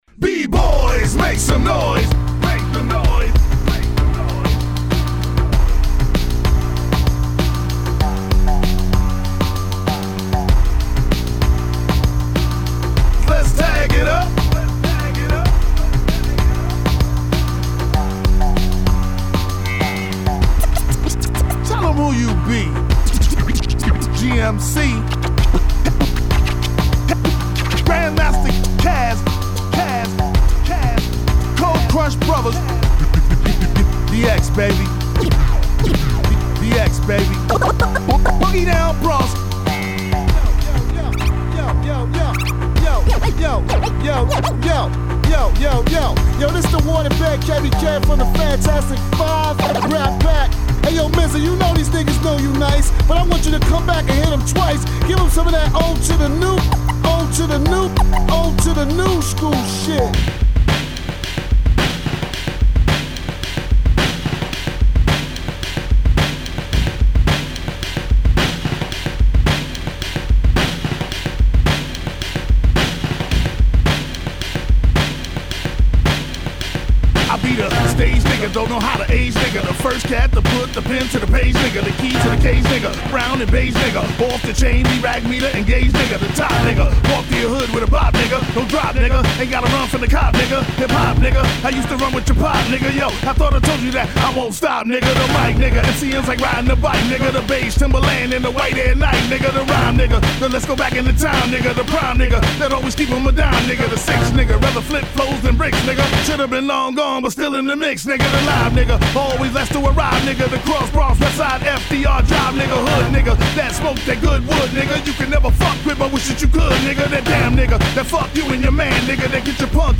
emu sp1200